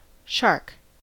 shark-us.mp3